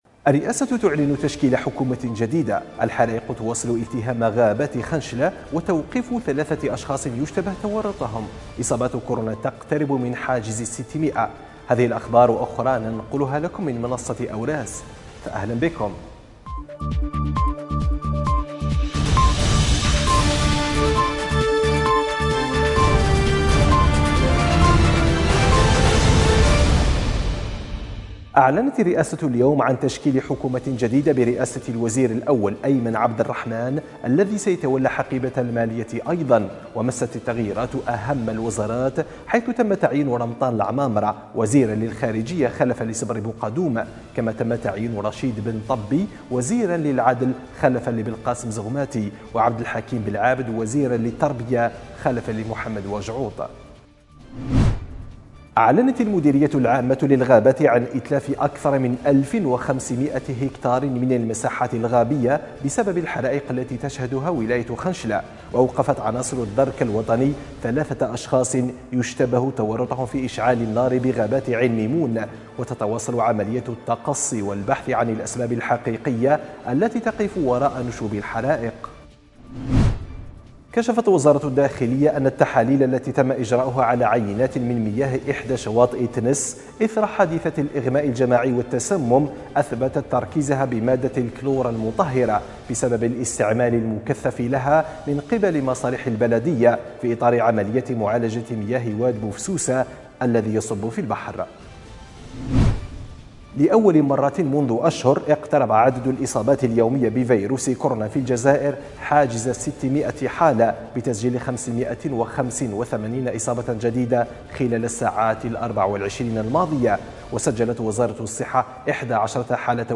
النشرة الرقميةفي دقيقتين